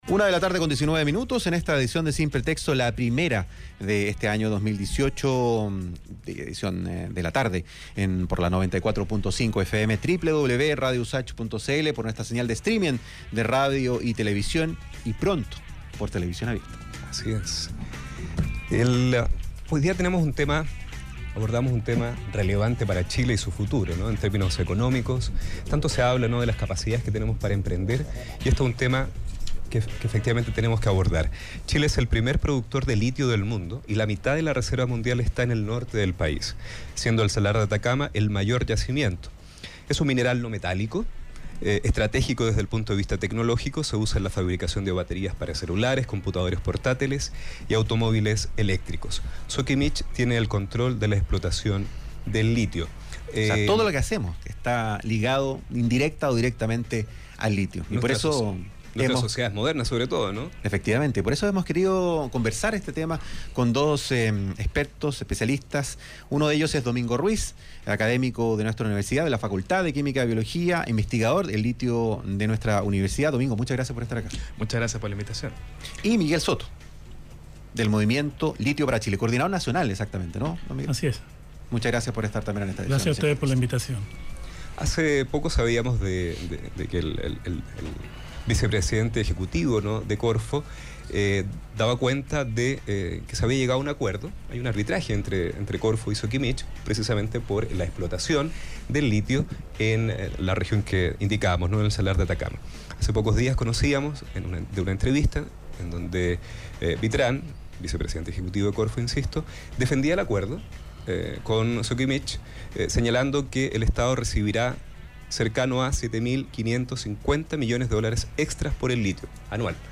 participó en el programa «Sin Pretexto» de la radio USACH, donde se manifestó contrario al acuerdo entre Corfo y SQM, que mantiene el control de la explotación del litio en manos privadas.